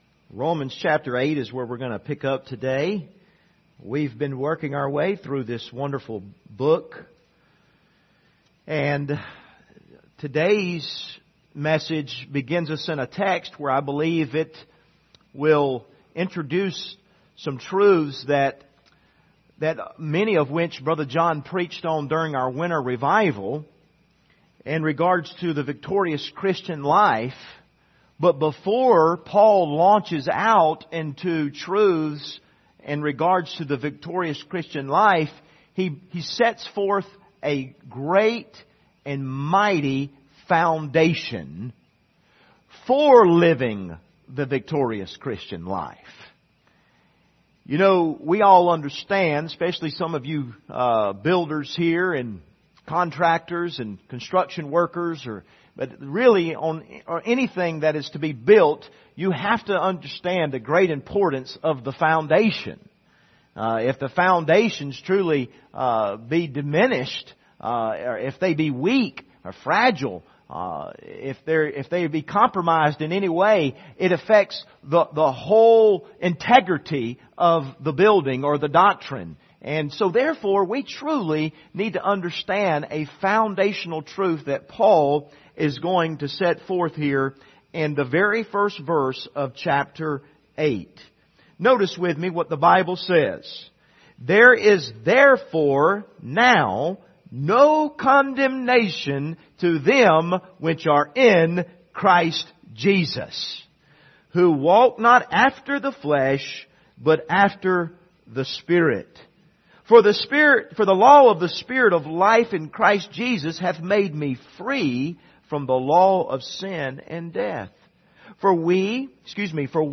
Passage: Romans 8:1-4 Service Type: Sunday Morning